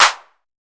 kits/DY Krazy/Claps/17.wav at main